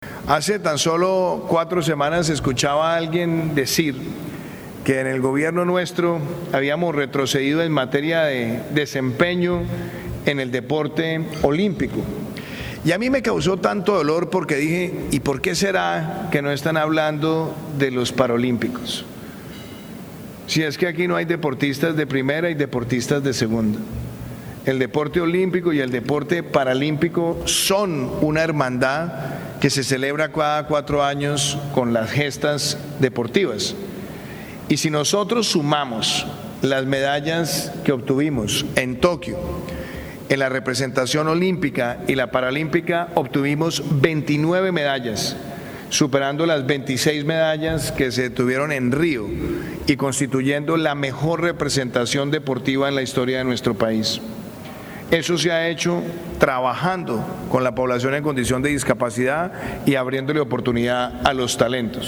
Durante este encuentro organizado por Nueva Economía Fórum en el Casino Gran Círculo de Madrid, Duque agradeció el respaldo y el trabajo de la ONCE, cuyo presidente Miguel Carballeda asistió en primera fila al encuentro, después de recibir además una copia del cupón enmarcado que la ONCE dedica a este país en su sorteo del próximo 6 de diciembre, con el lema ‘Colombia diversa y vital’.